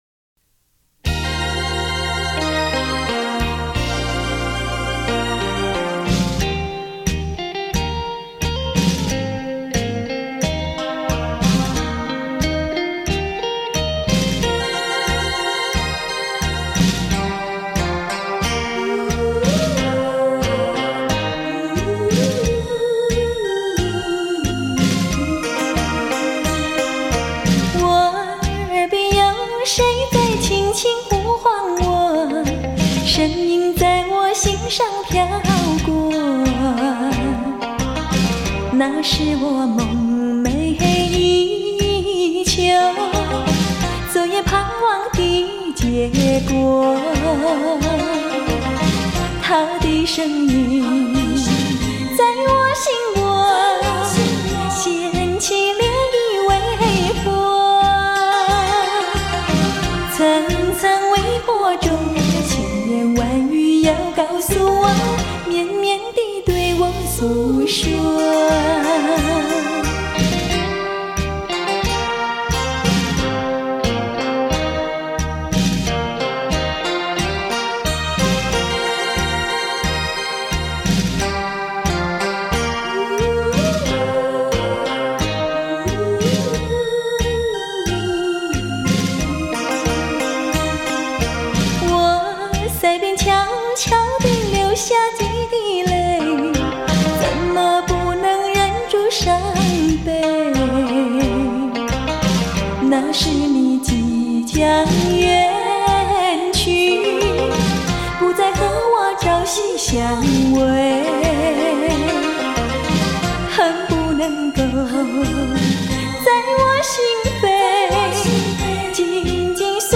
典雅温婉的歌声